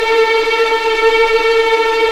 Index of /90_sSampleCDs/Roland LCDP08 Symphony Orchestra/STR_Vls Tremolo/STR_Vls Trem wh%